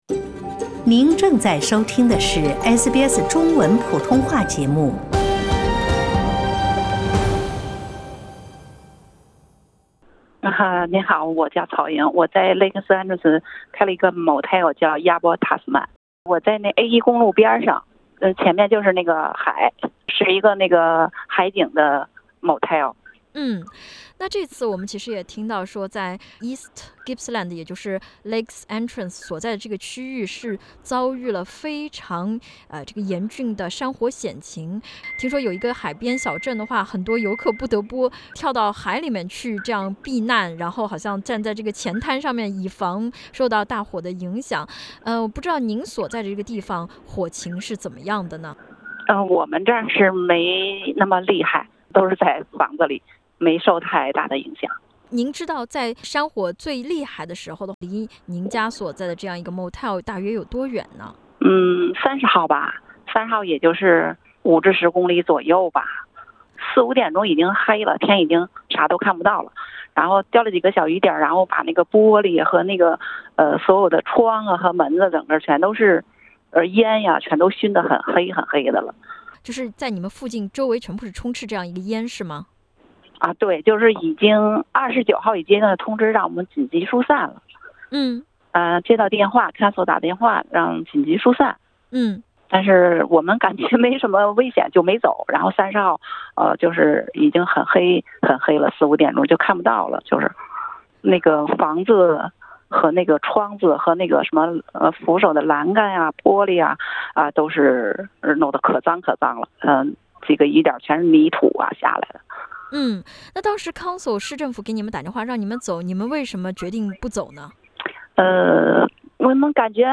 那么对于在澳洲亲人朋友不多的移民来说，转移到哪里比较好，还有要如何为山火撤离提前做准备呢？欢迎点击封面图片收听音频采访。